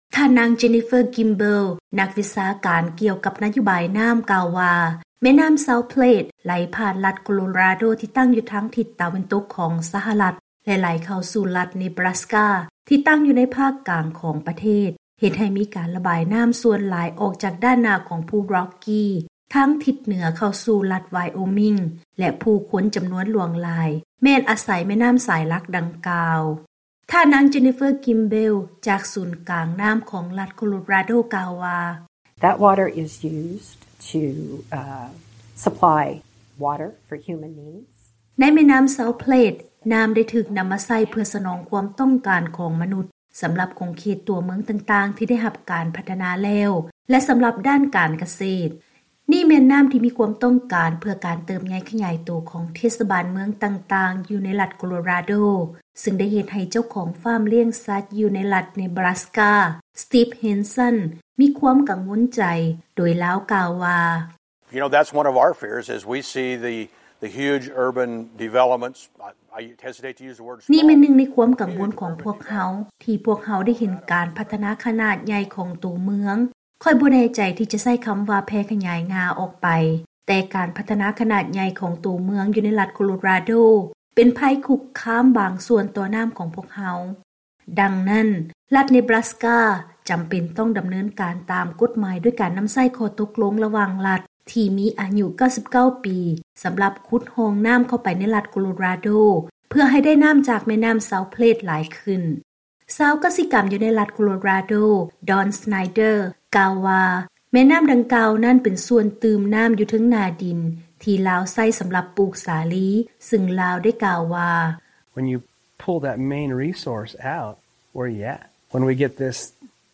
ເຊີນຮັບຟັງບົດລາຍງານກ່ຽວກັບ ຄວາມພະຍາຍາມໃນການຂຸດຮ່ອງນໍ້າຂອງລັດ ເນບຣາສກາ.